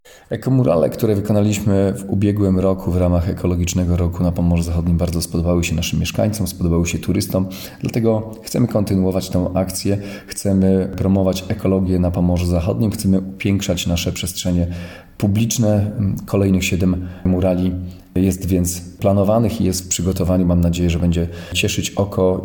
– Mówi Olgierd Geblewicz, Marszałek Województwa.